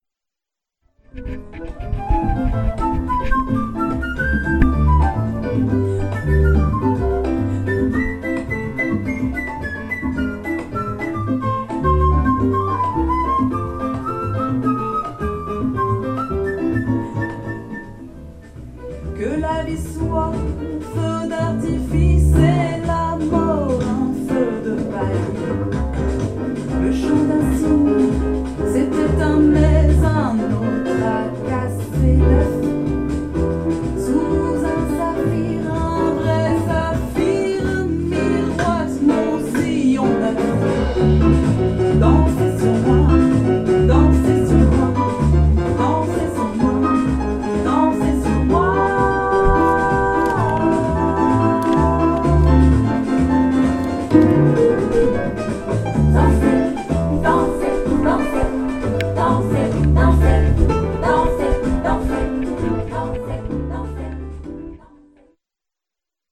trompette, batterie, percussions, chant
saxophone, flûte, chant
contrebasse